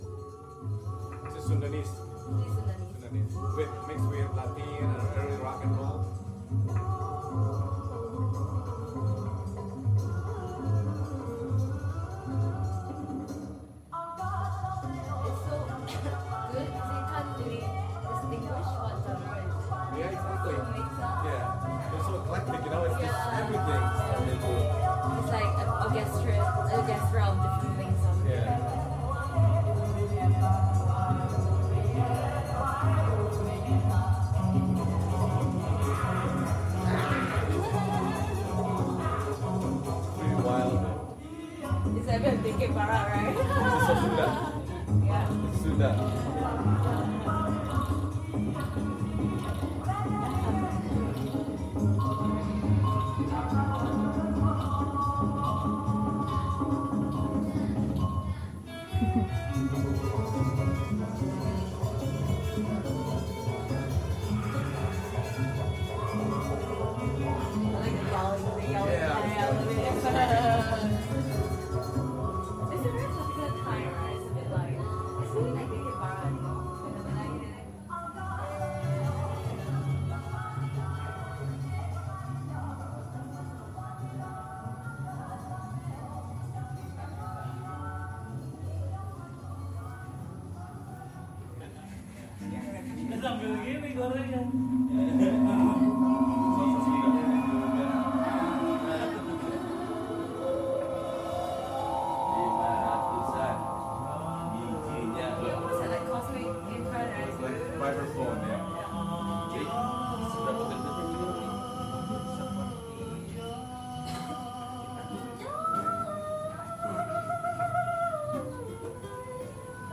Saturday afternoons on WGXC feature Wave Farm Artists-in-Residence, and special guests, who are interviewed on-air, present past and future projects, as well as perform live.